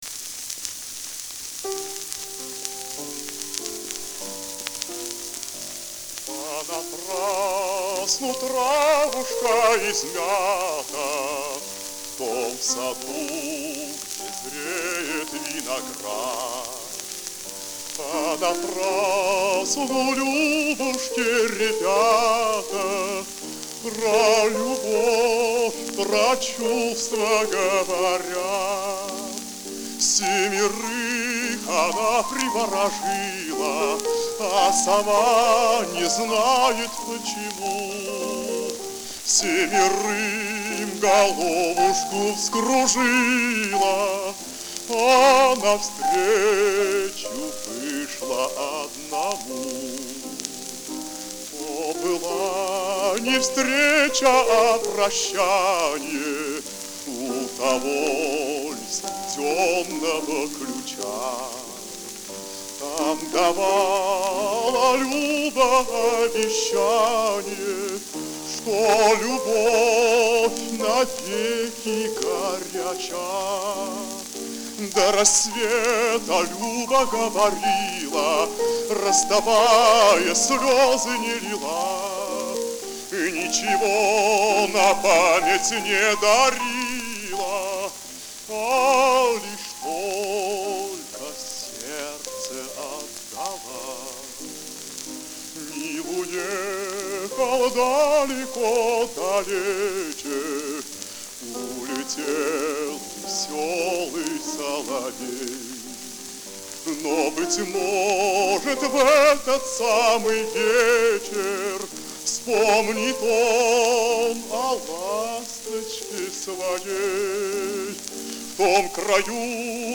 Ф-но